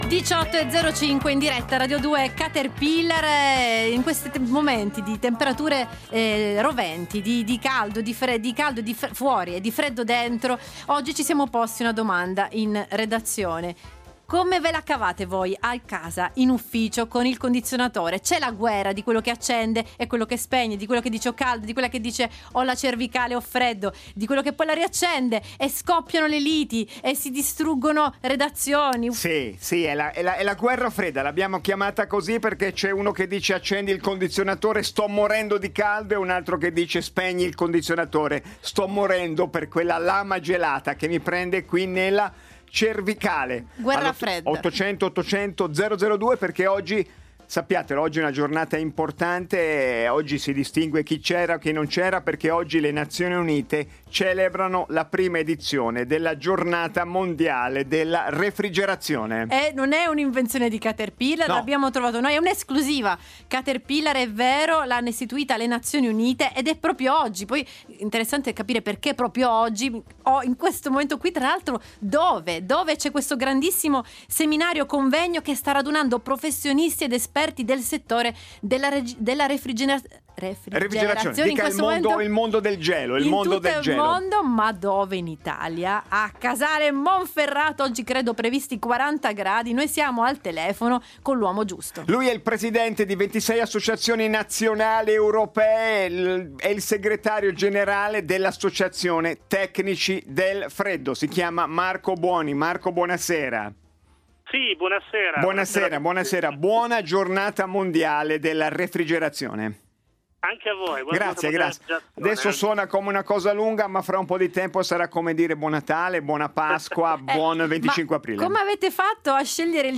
L’audio dell’intervista è disponibile di seguito.